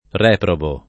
reprobo [ r $ probo ]